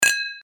Чоканье хрустальных бокалов